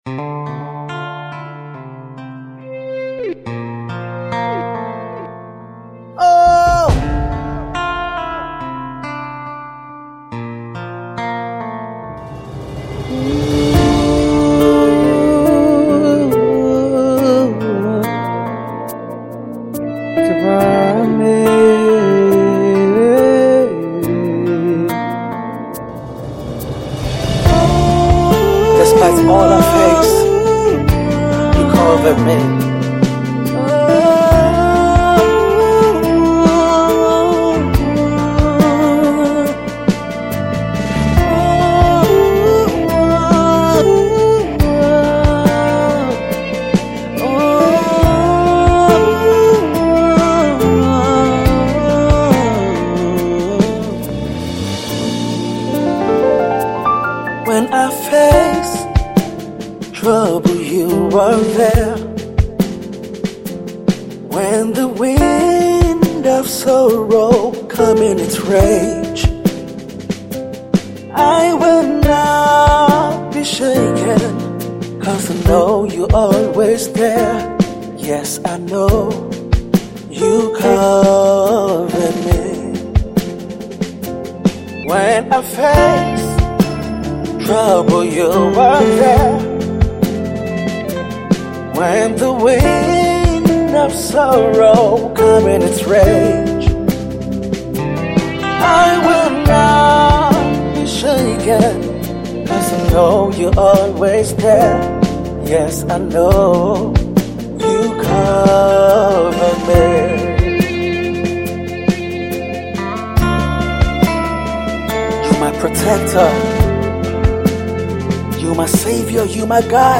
Inspiring and indie gospel music act